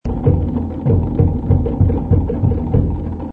AKAI GX 636 umgebaut für Magnettonwiedergabe
Aus unserem Filmfundus haben wir eine 120 Meter Super8 Filmspule mit Stereoton von Weltreiseaufnahmen aus den 70er Jahren herausgesucht und 12 kurze Samples zum kurzen Anspielen für Sie angefertigt: